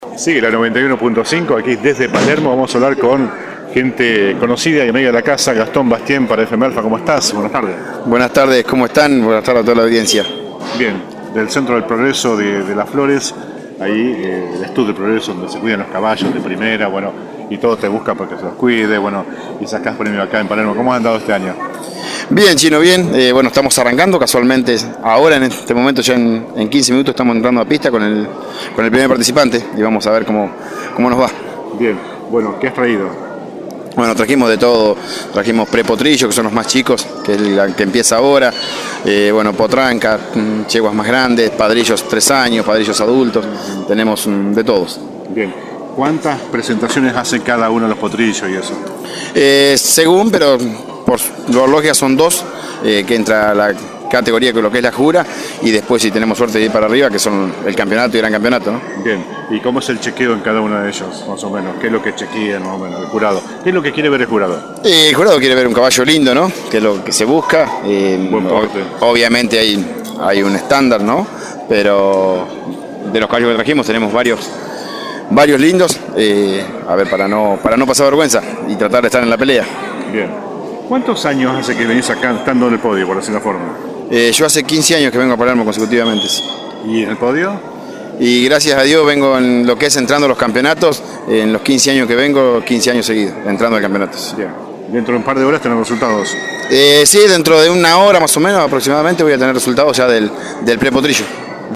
La 91.5 continúa con su cobertura periodística desde la Exposición Rural de Palermo junto a nuestros expositores y representantes.